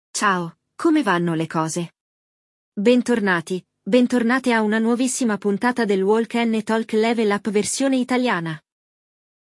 Neste episódio do Walk ‘n’ Talk Level Up, vamos acompanhar duas amigas e o que farão para o jantar.
No episódio de hoje, vamos acompanhar uma conversa entre duas amigas: uma delas está com fome, mas percebe que está um pouco tarde para um aperitivo.
Il dialogo